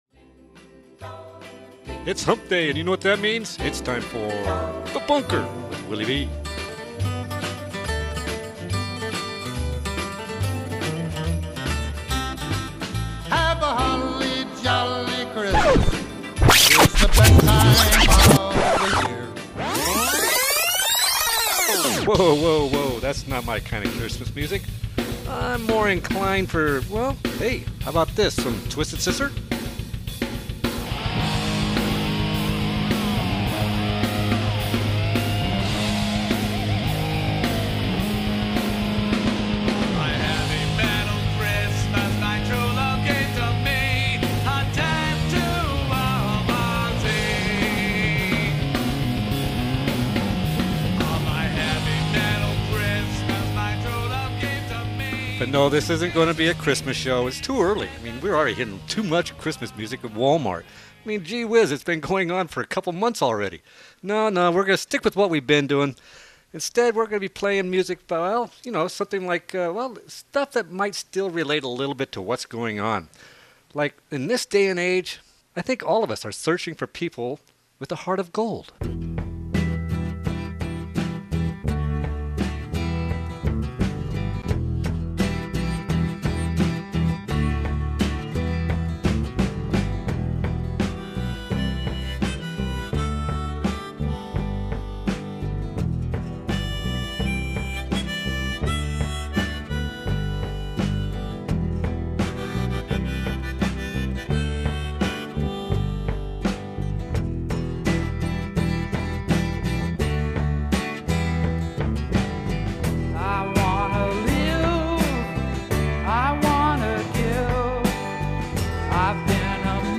This week's show features good music that is just about everything but Christmas music.